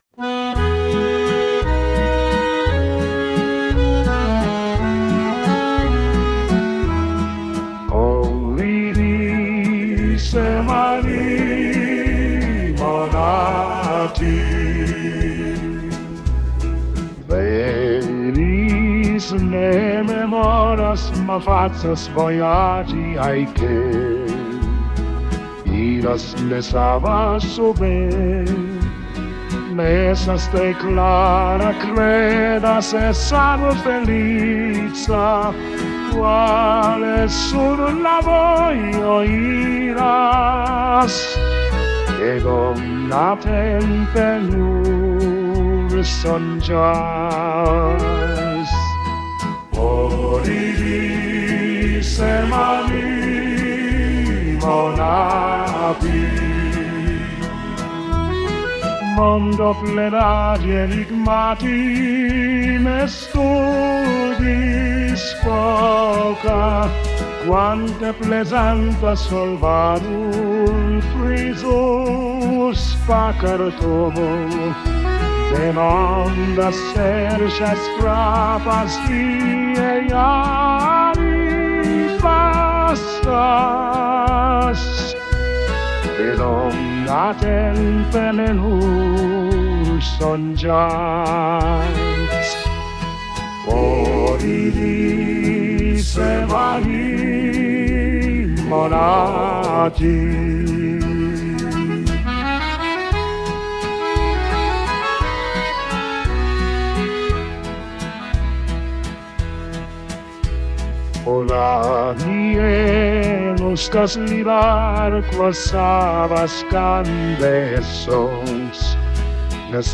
kansoni valso